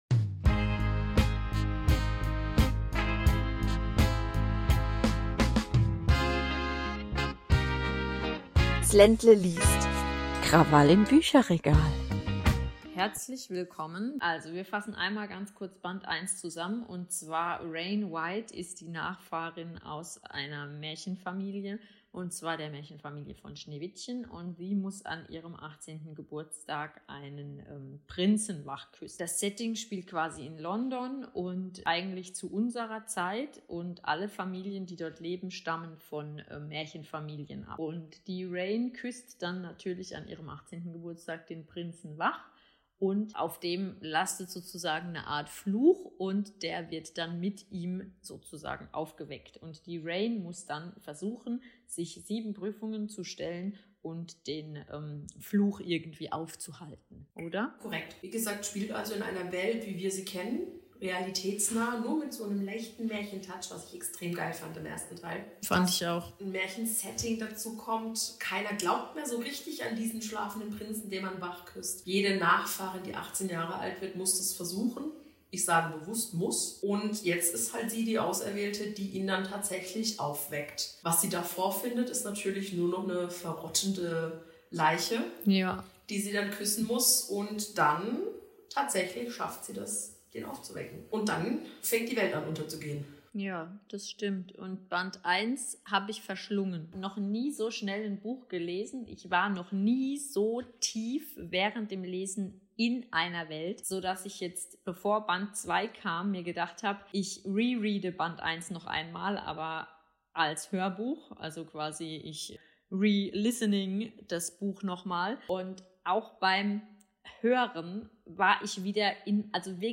Wir entschuldigen uns jetzt schon für den Ton, leider ist während der Aufnahme ein Mikro kaputtgegangen, aber wir wollen euch die Folge dennoch nicht vorenthalten.